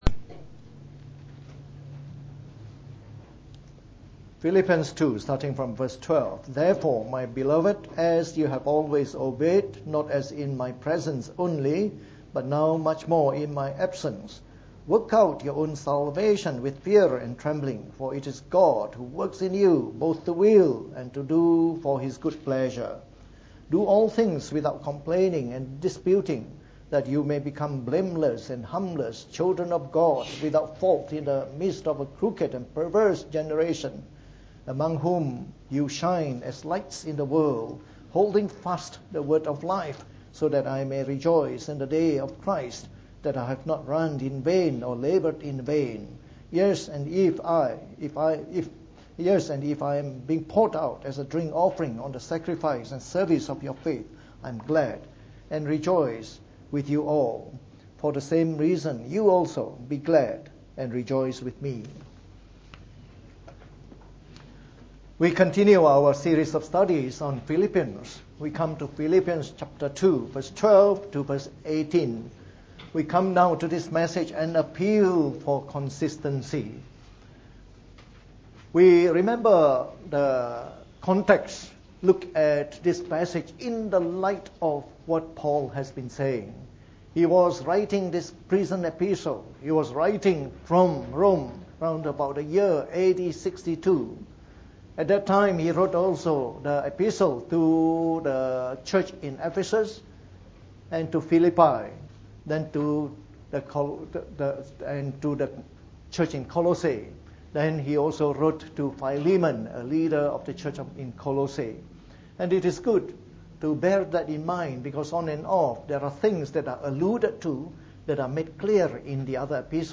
Preached on the 12th of February 2017. From our series on the Epistle to the Philippians delivered in the Morning Service.